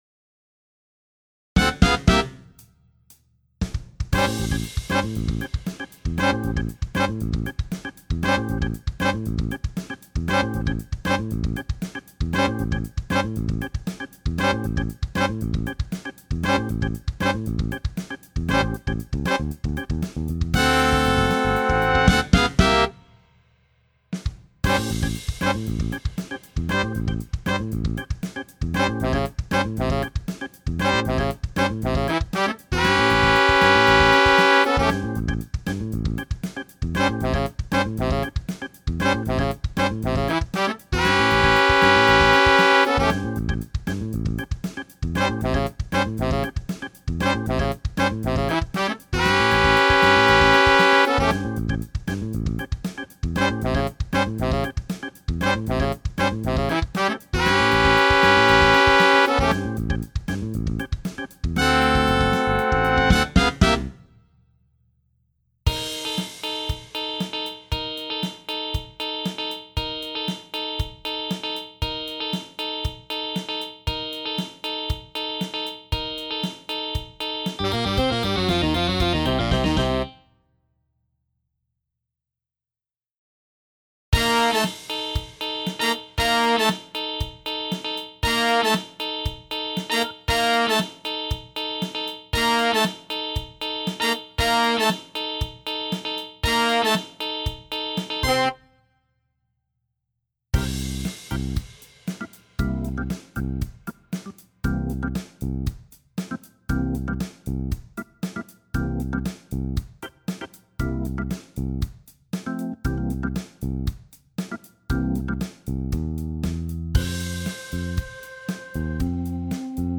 Features rock guitar